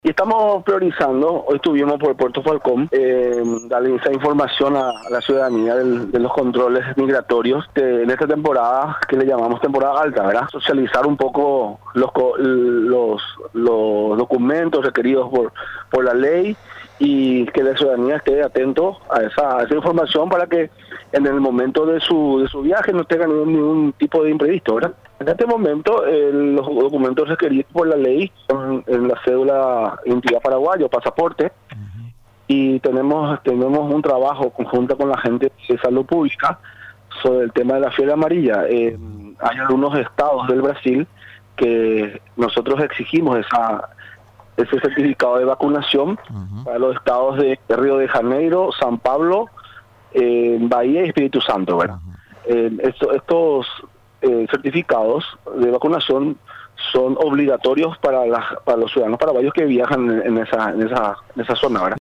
Fabio Espinosa, jefe de Gabinete de la Oficina de Migraciones, habló sobre las prioridades que impulsa la entidad en estas fechas de mayor movimiento migratorio. En ese sentido, refirió que se abocan en dar a conocer a la ciudadanía, todos los documentos requeridos por la entidad a la hora de viajar.